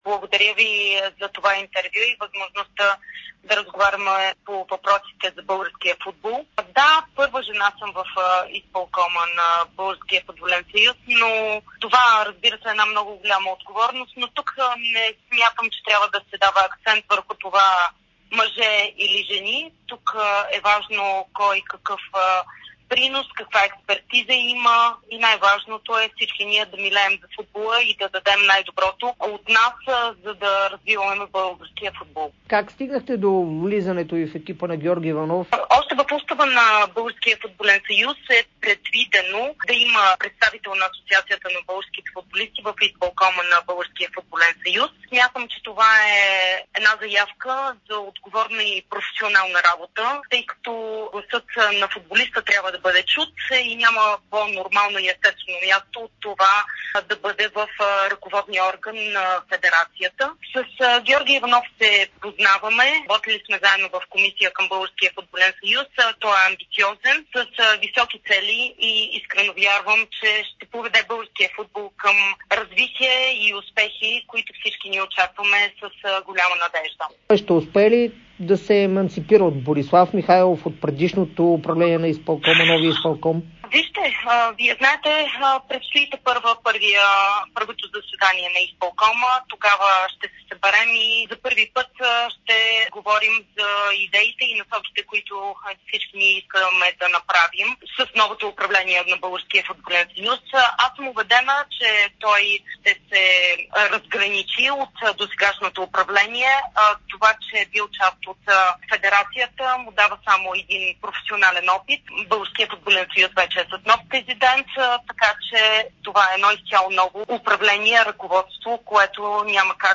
Благодаря ви за това интервю и възможността да разговаряме по въпросите за българския футбол.